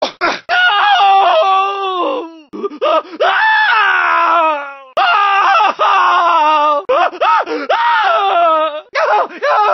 gta-san-andreas-pedestrian-voices-coughing-pain-male-audiotrimmer_RvaUSLo.mp3